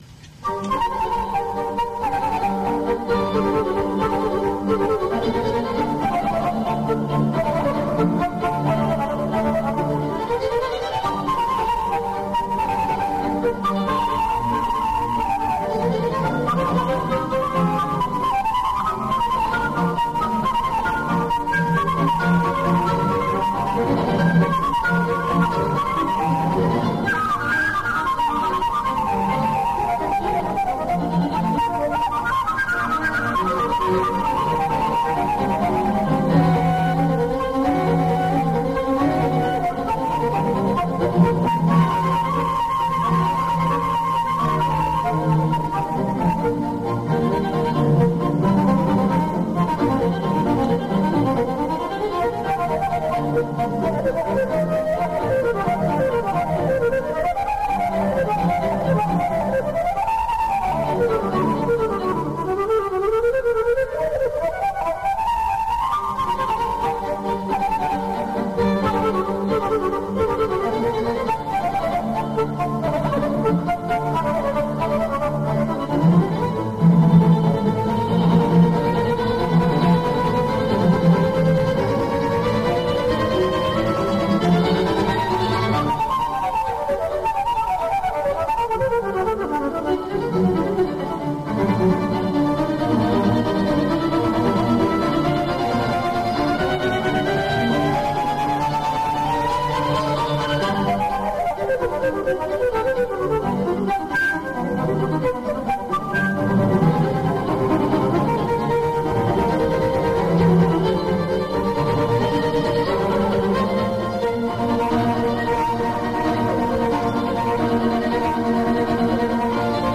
-pesa-klassicheskaya-s-fleytoy-opoznat-.mp3